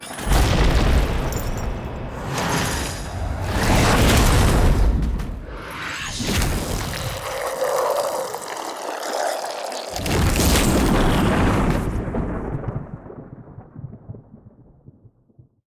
WazardAttacksSFXA.ogg